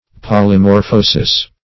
Search Result for " polymorphosis" : The Collaborative International Dictionary of English v.0.48: Polymorphosis \Pol`y*mor*pho"sis\, n. [NL.
polymorphosis.mp3